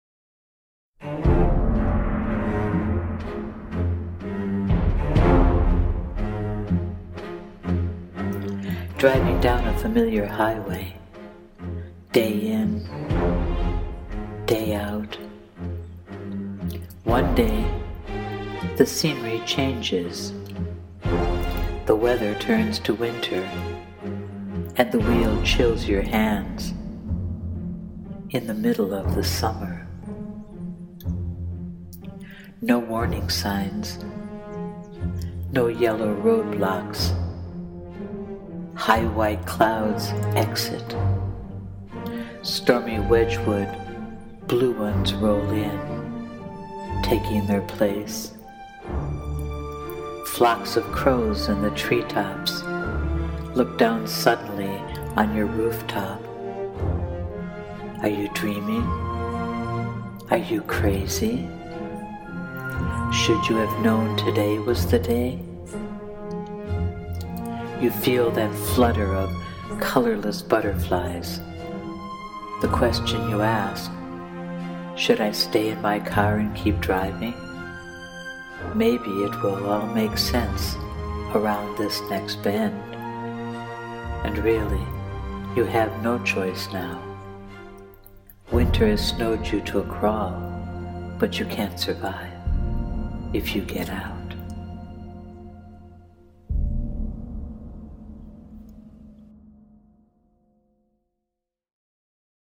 Please Click Play To Hear Me Recite It to the music that inspired it: “Dodd’s Shakedown” by Jeff Russo and the Prague Philharmonic Fargo Season 2